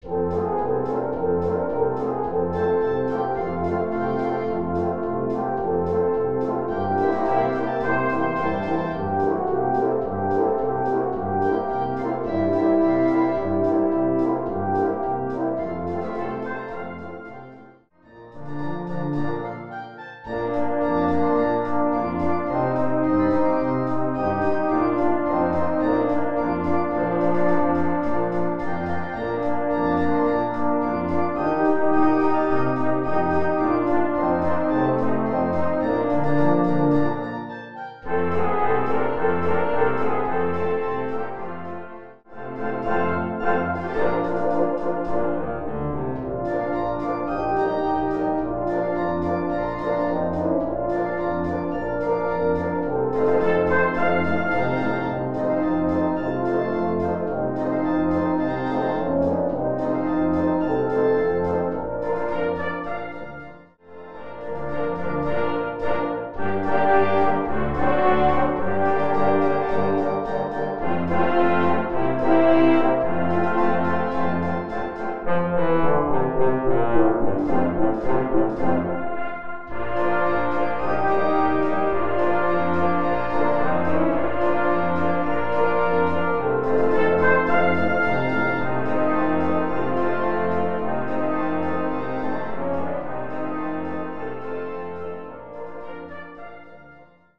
sehr gefällige Polka im böhmischen Stil